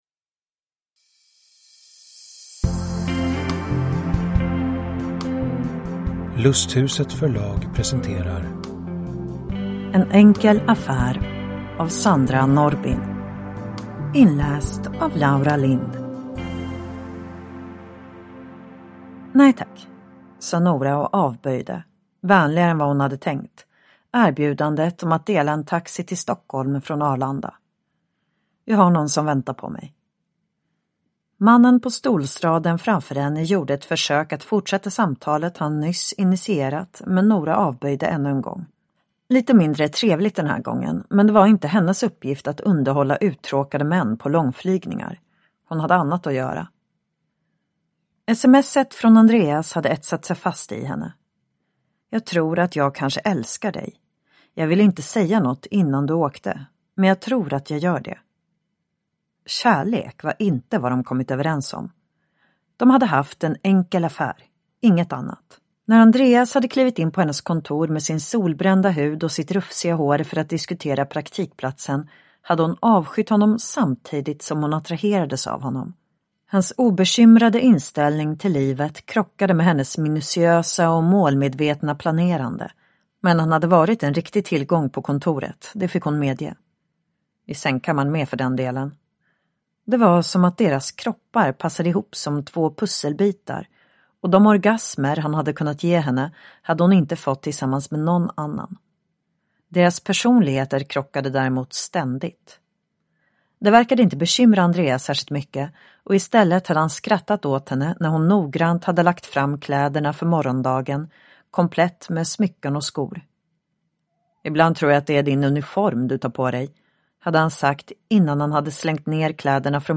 En enkel affär (ljudbok) av Sandra Norrbin